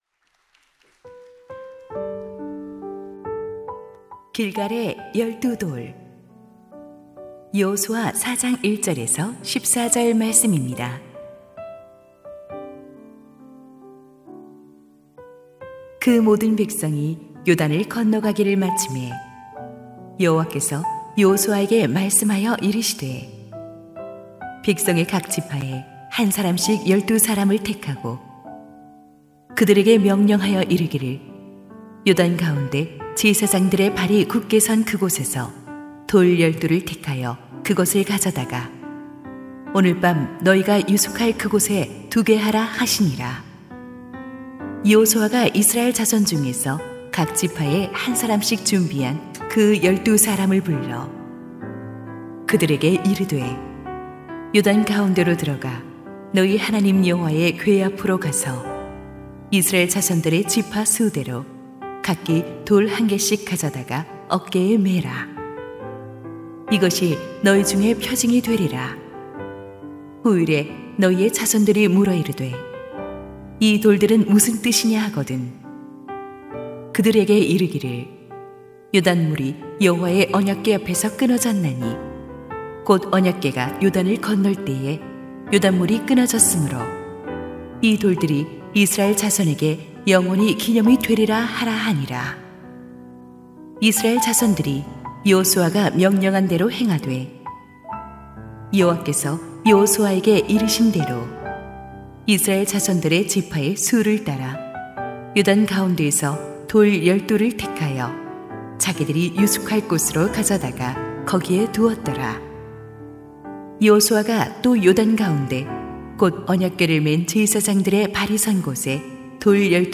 2025-09-03 약속의 땅을 위한 특별새벽기도회
> 설교